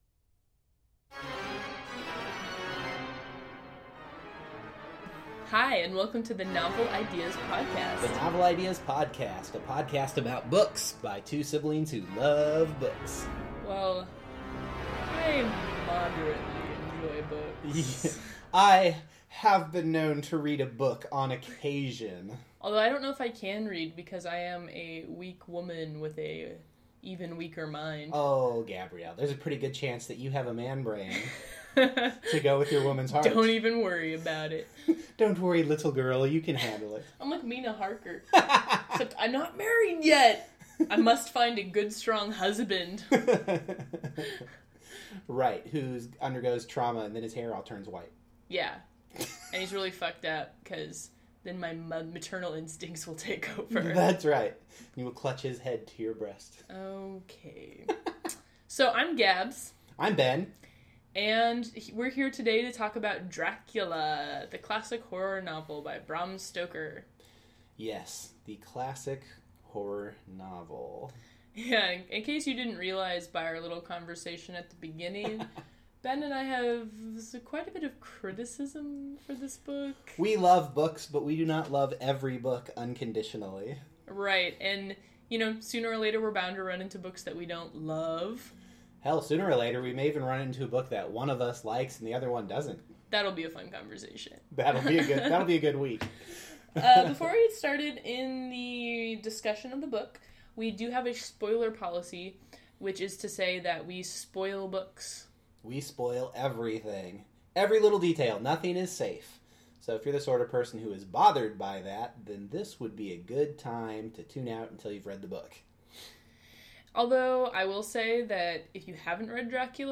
It’s a tad anachronistic, but what self respecting, centuries old, avatar of evil doesn’t want some creepy organ music in the background?